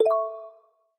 notifier_bloom.opus